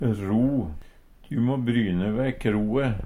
ro - Numedalsmål (en-US)